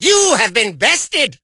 mortis_kill_01.ogg